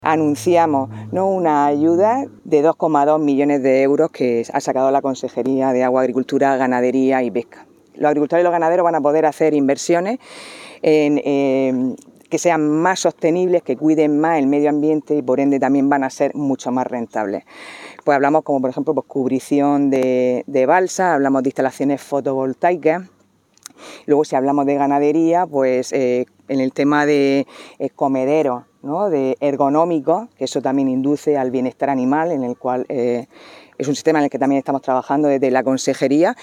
Vota: | Resultado: 4 votos Categoría nota prensa: Agua, Agricultura, Ganadería y Pesca Contenidos Asociados: Declaraciones de la consejera Sara Rubira sobre la ayudas a inversiones que van a recibir agri